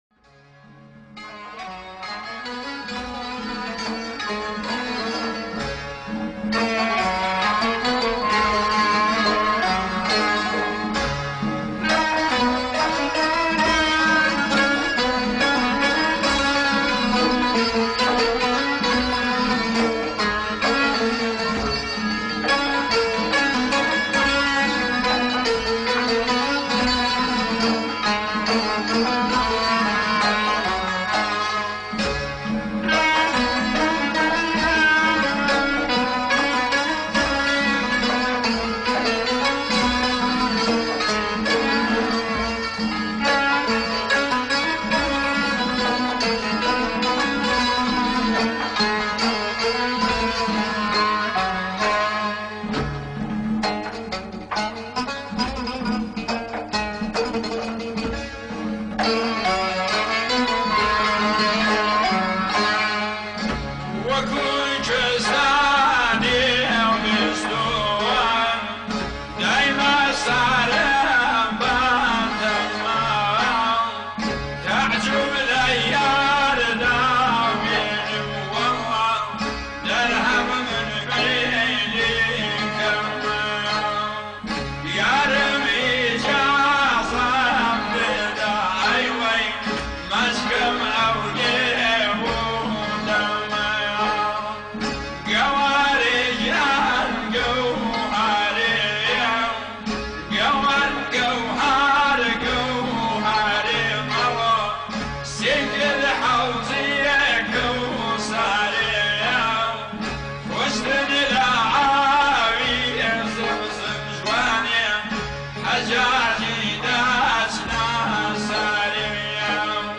آهنگ کردی قدیمی ، آهنگ کردی فولکلور